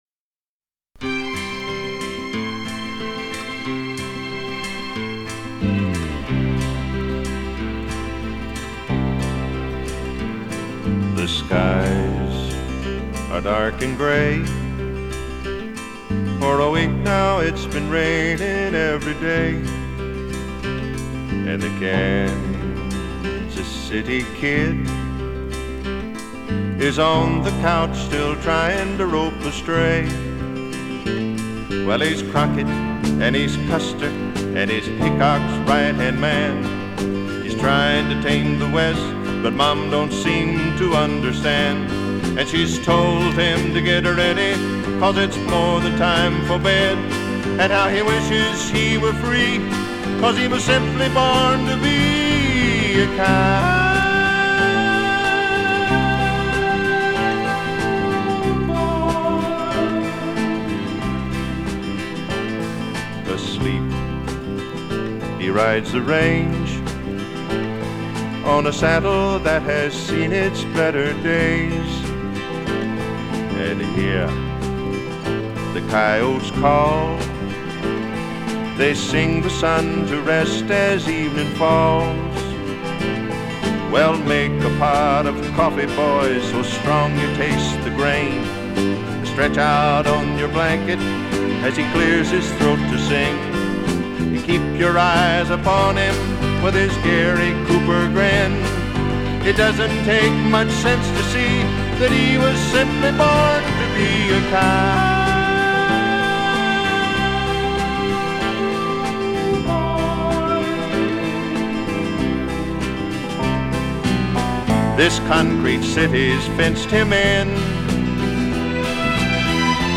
Country & Western tune